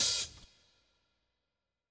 BWB THE WAVE OPEN HAT (15).wav